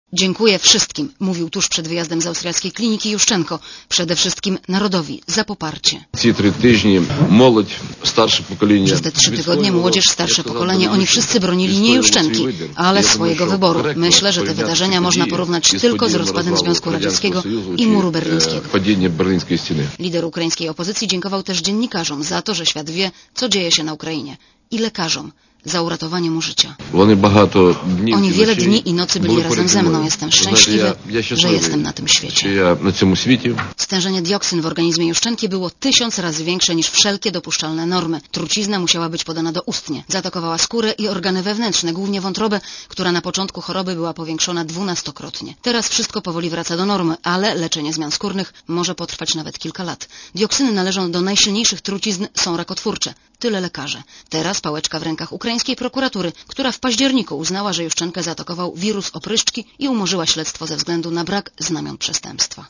reporterki Radia ZET*